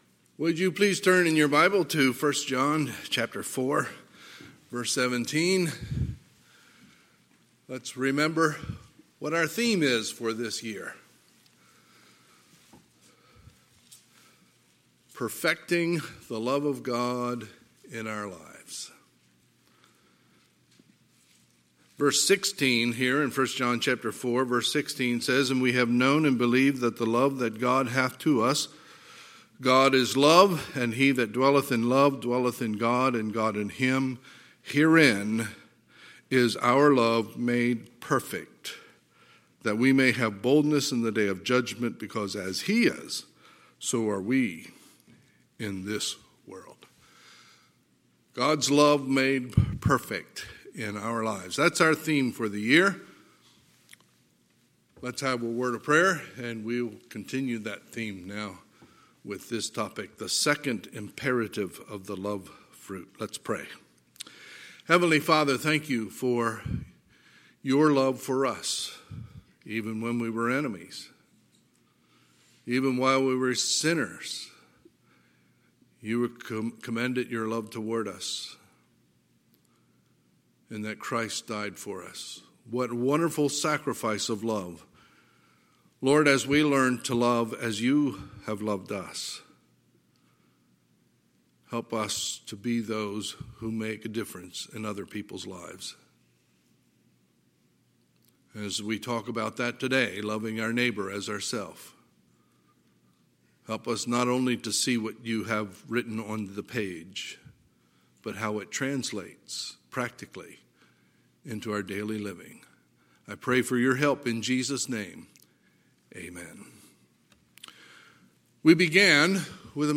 Sunday, February 14, 2021 – Sunday AM